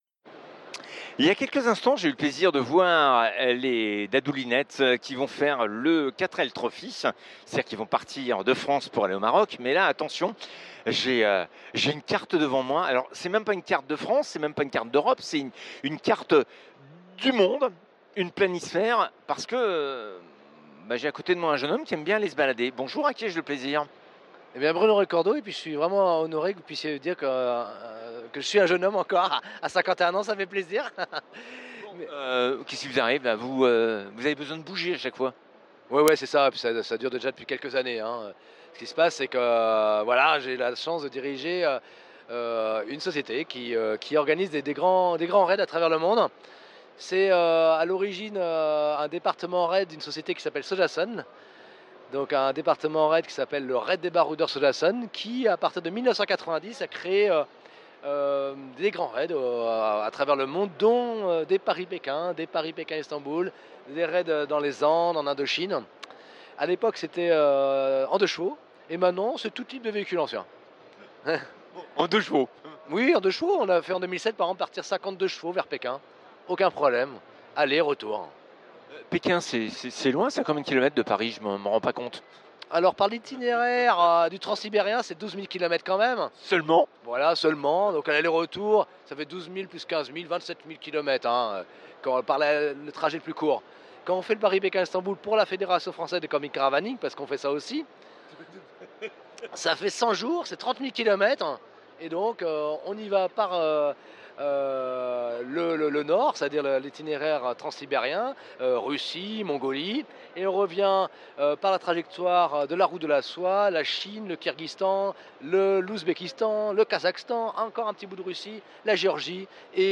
en direct d'Epoqu'Auto 21